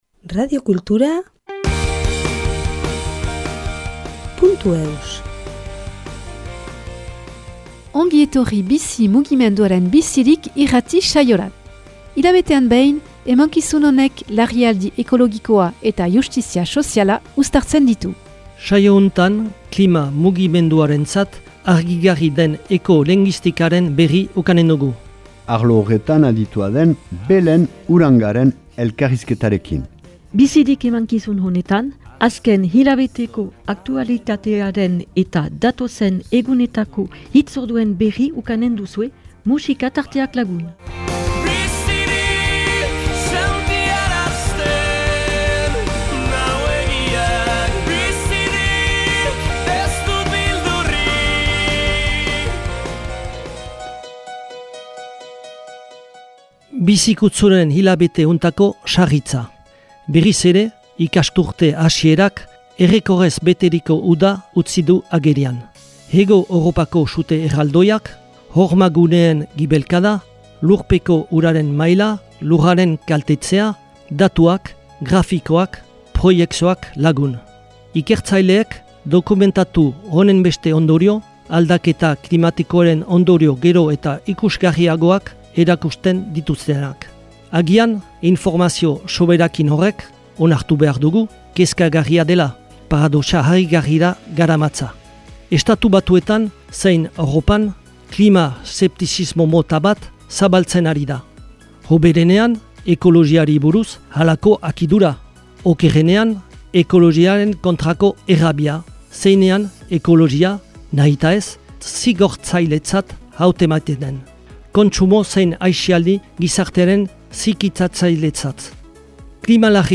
Enregistrement émission de radio en langue basque #128